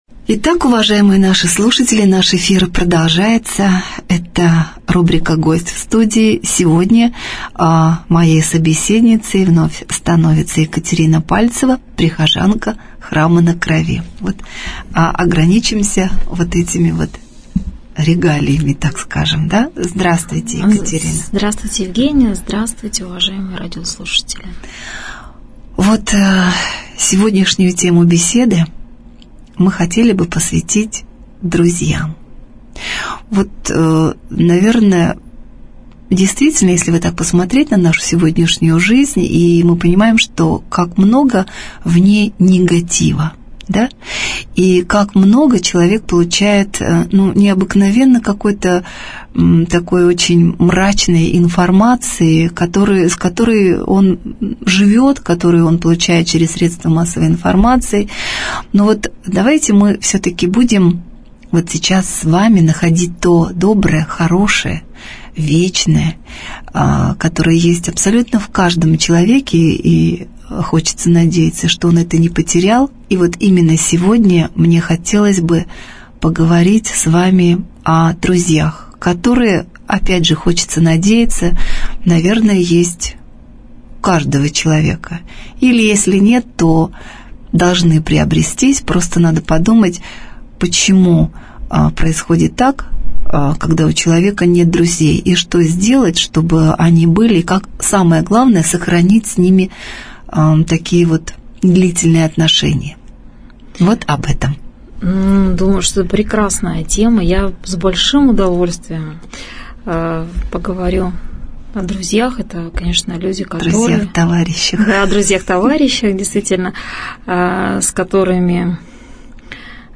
Гость в студии | Православное радио «Воскресение»